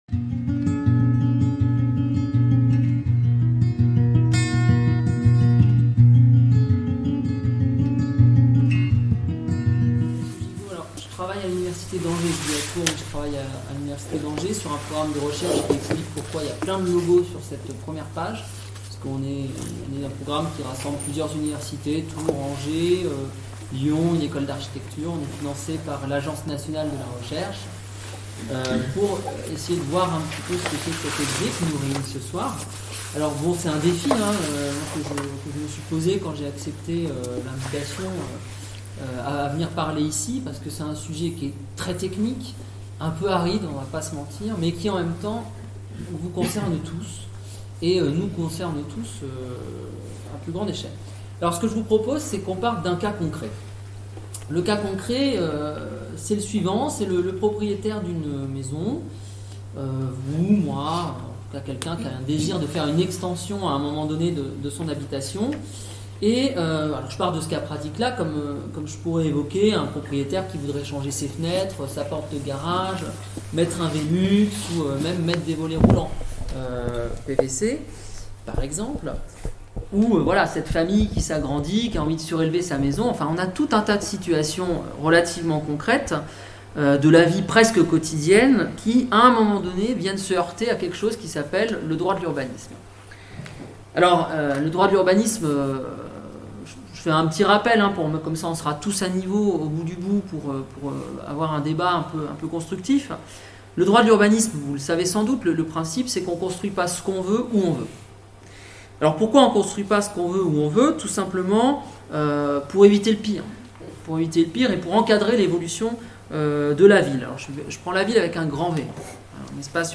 Conférence donnée dans le cadre de l'université sur Lie, organisée par le Pays d'art et d'histoire du Vignoble Nantais.